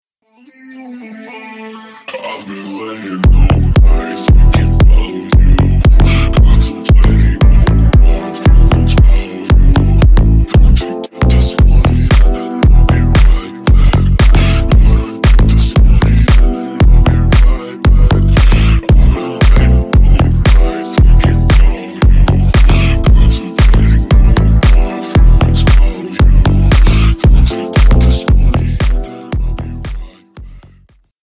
Клубные Рингтоны
Танцевальные Рингтоны